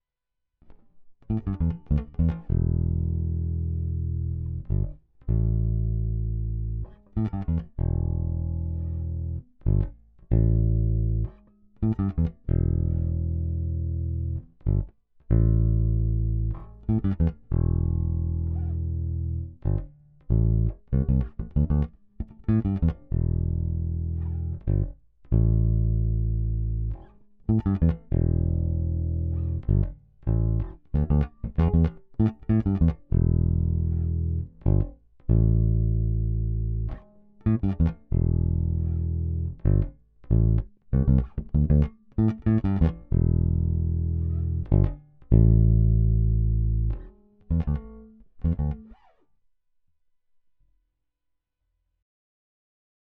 Nahráváno na Fender Precision American Vintage 2.
Nahrávky bez bicích:
Dry bez bicich